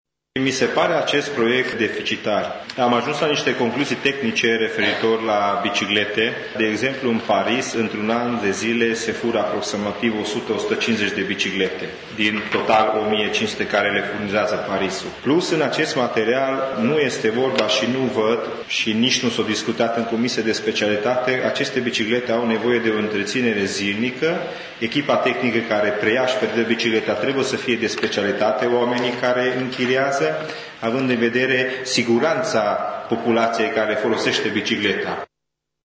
Consilierul UDMR Josza Tibor a spus, în plenul şedinţei, că proiectul este deficitar deoarece problemele tehnice legate de poziţionarea punctelor de închiere, asigurarea pazei şi a întreţinerii bicicletelor nu sunt explicate: